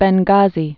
(bĕn-gäzē, bĕng-)